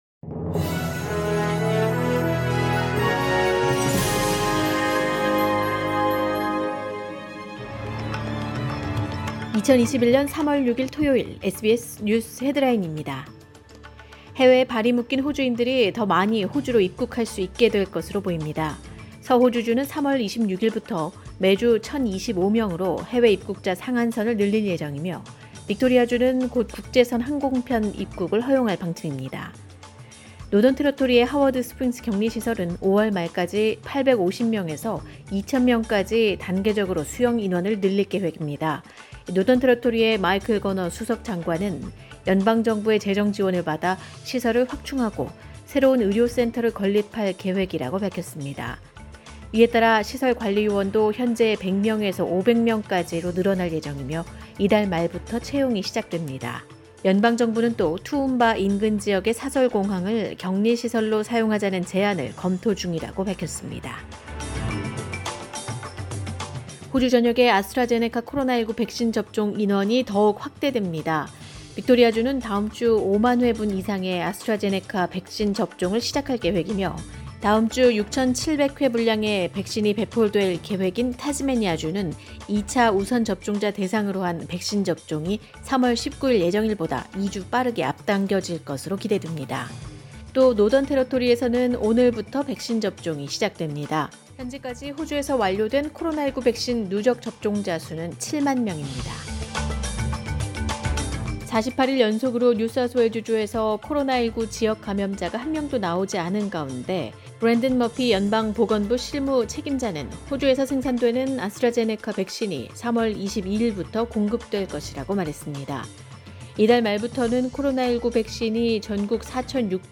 2021년 3월 6일 토요일 SBS 뉴스 헤드라인입니다.